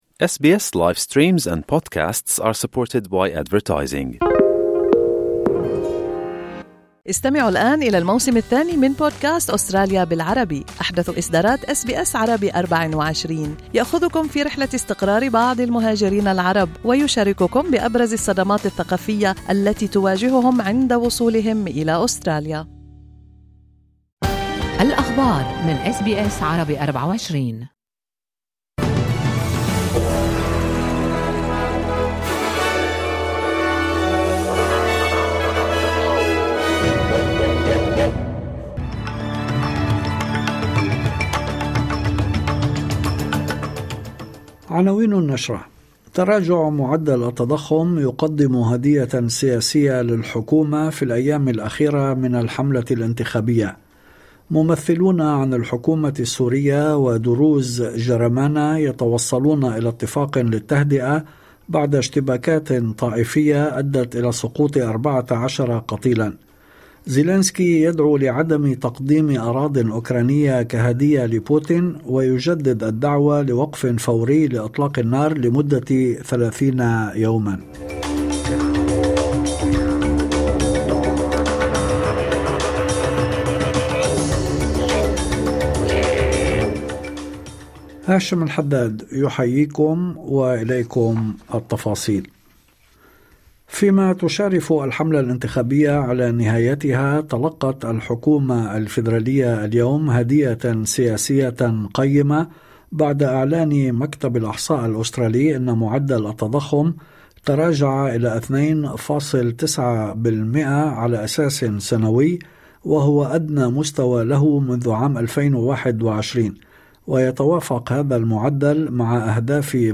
نشرة أخبار المساء 30/04/2025